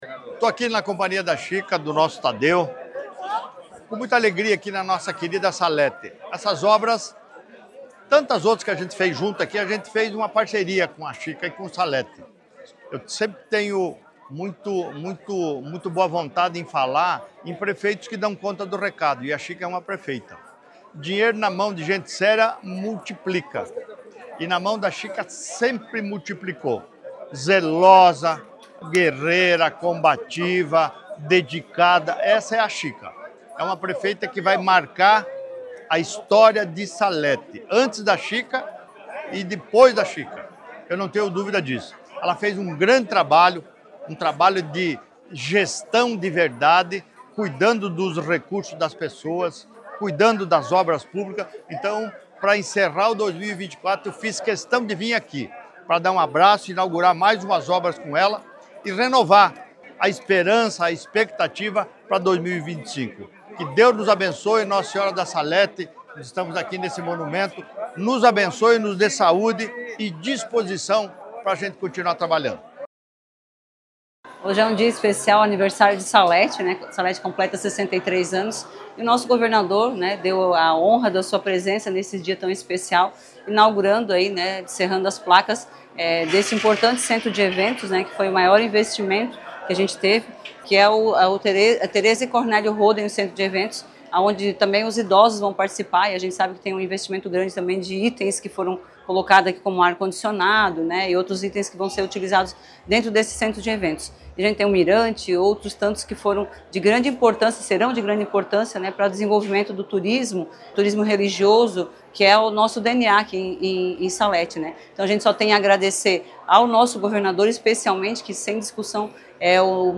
Durante o ato, o governador Jorginho Mello destacou a parceria entre o Governo do Estado e o município de Salete, reforçando o compromisso com o desenvolvimento das cidades catarinenses:
A prefeita da cidade, Solange Schlichting, agradeceu ao governador por todo o investimento que ele tem trazido para a cidade: